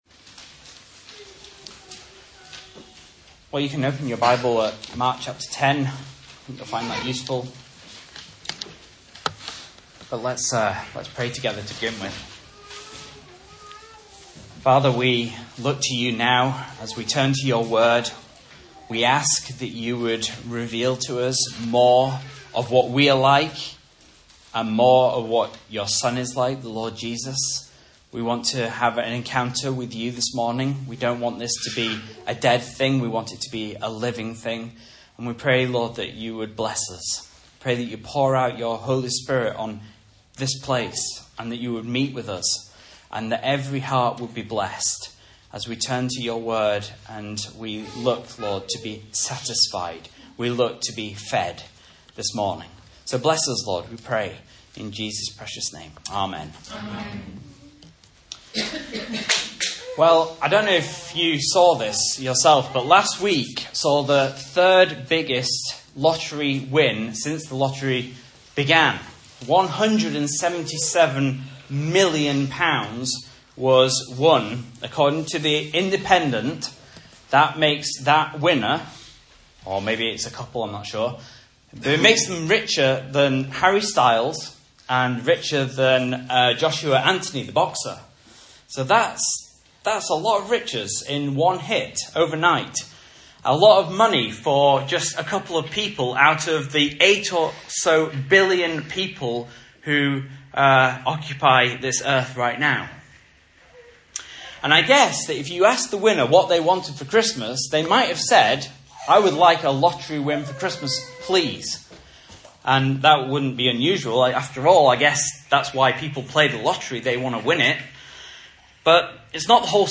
Message Scripture: Mark 10:35-52 | Listen